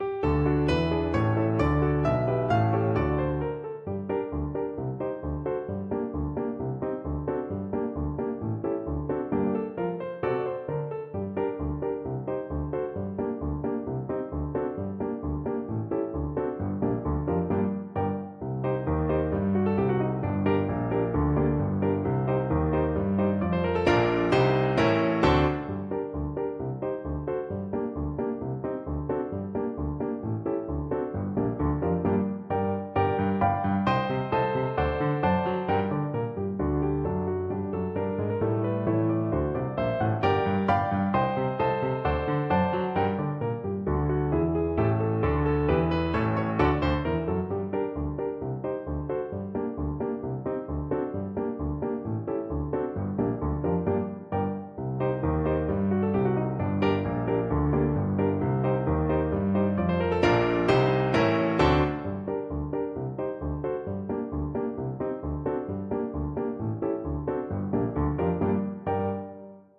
French Horn
G minor (Sounding Pitch) D minor (French Horn in F) (View more G minor Music for French Horn )
2/4 (View more 2/4 Music)
Traditional (View more Traditional French Horn Music)
world (View more world French Horn Music)